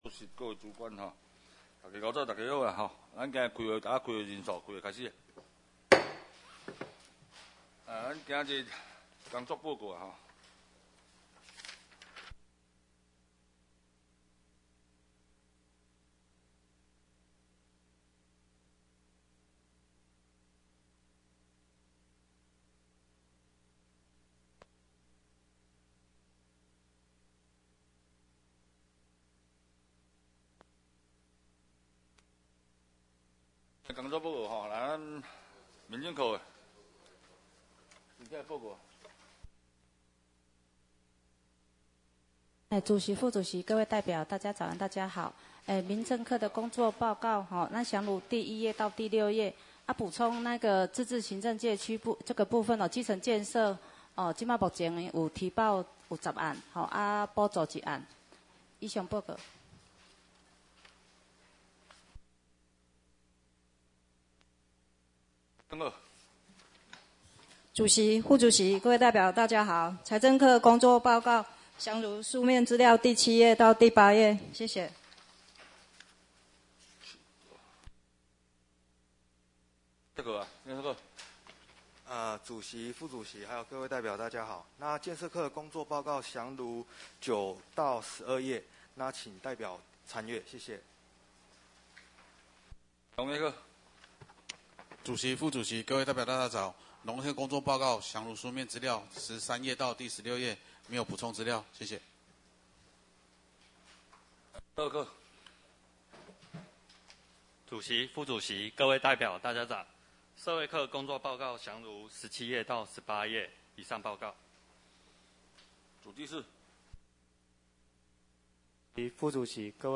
第22屆代表會議事錄音檔